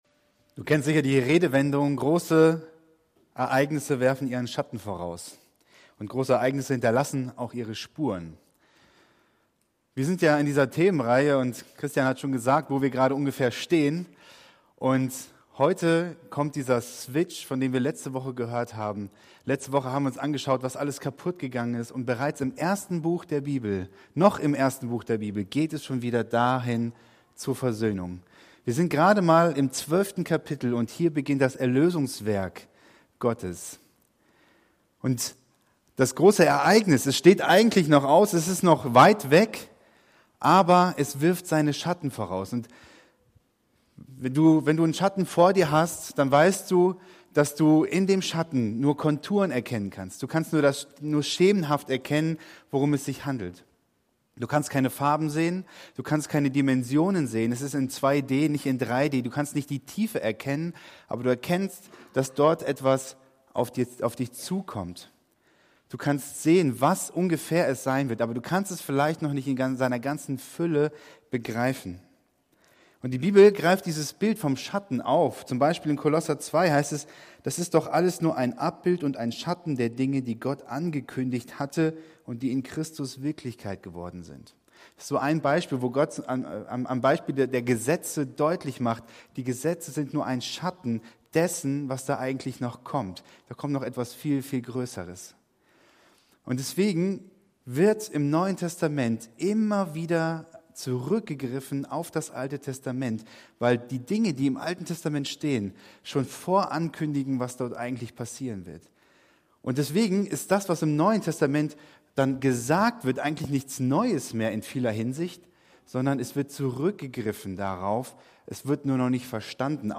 Predigt-Podcast vom 29. Januar 2023 aus dem FORUM HOFFNUNG in Dresden.